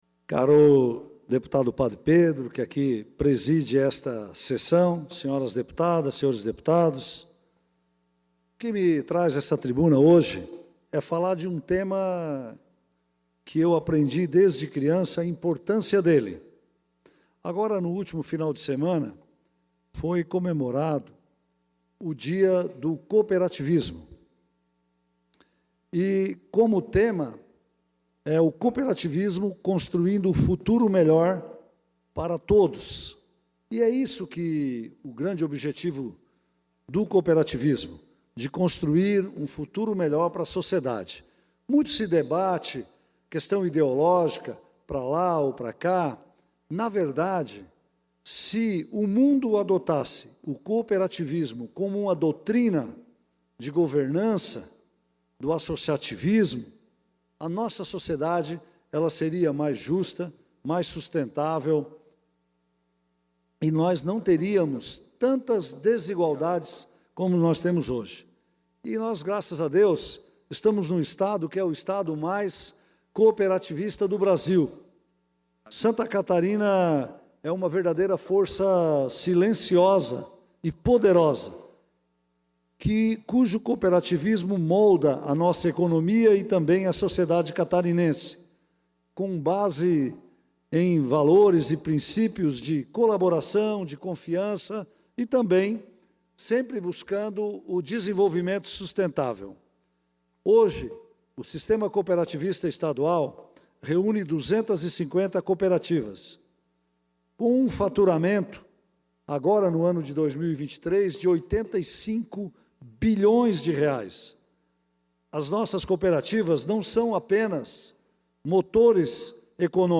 Pronunciamentos dos Deputados na sessão ordinária desta terça-feira (9)
- José Milton Scheffer (PP);